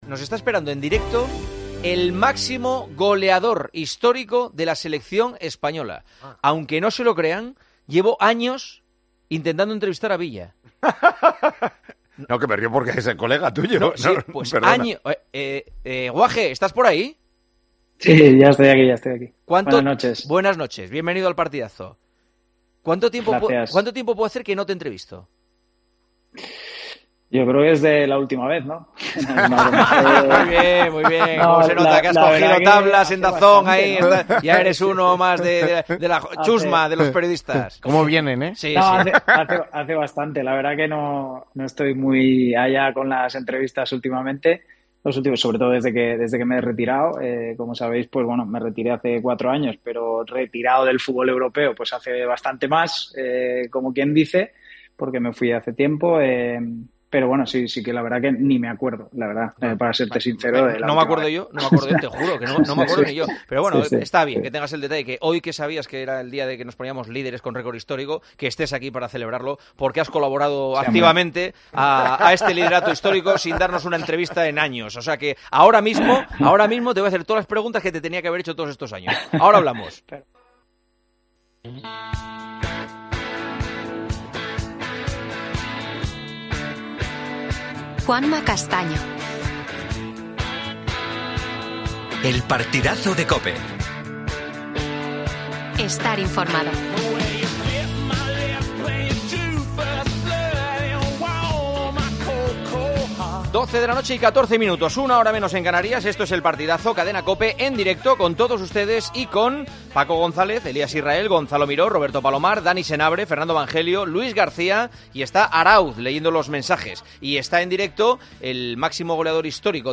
A solo tres días del partido de cuartos de final entre España y Alemania, El Partidazo de COPE ha entrevistado al máximo goleador de la Selección Española, el asturiano David Villa.